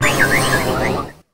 toedscruel_ambient.ogg